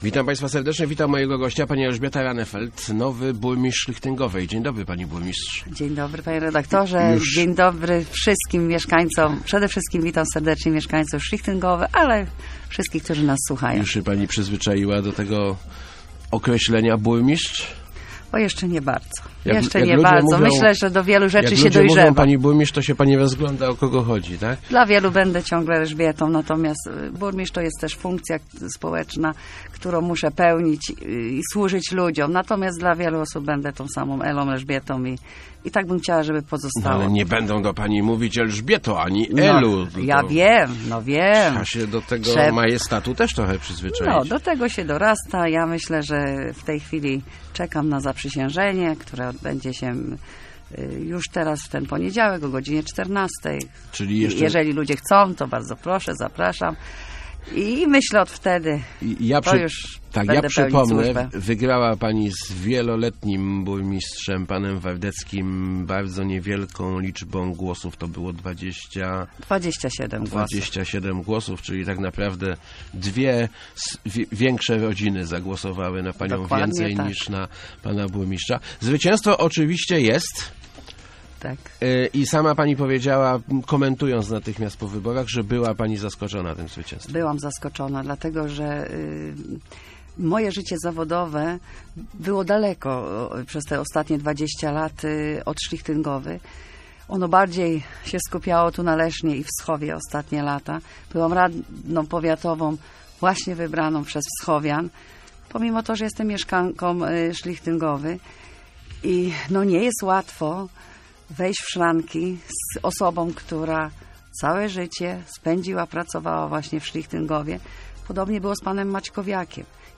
Do urzędu wejdę we wtorek rano, daję czas poprzednikowi na pożegnanie - mówiła w Rozmowach Elki Elżbieta Rahnefeld, burmistrz Szlichtyngowej. Zapewnia, że nie będzie "miotłą" dla urzędników, choć oczywiście ma już kandydata na swojego zastępcę.